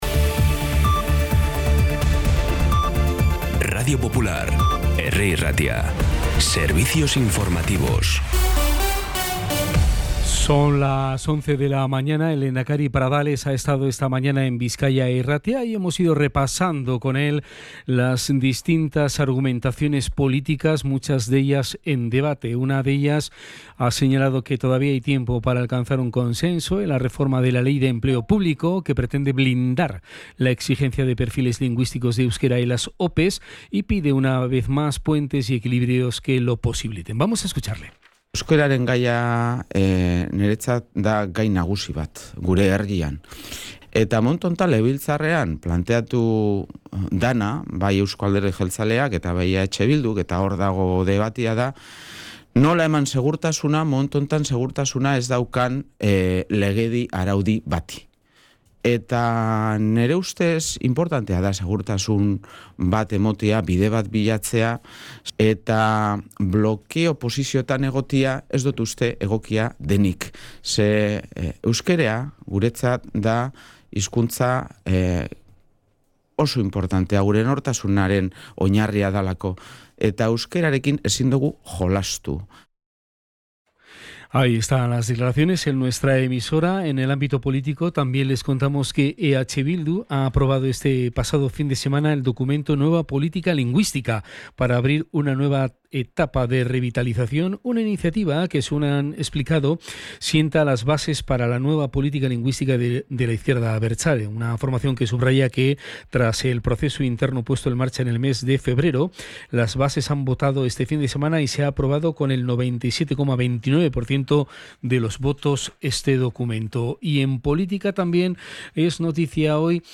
Las noticias de Bilbao y Bizkaia del 27 de abril a las 11
Podcast Informativos
Los titulares actualizados con las voces del día.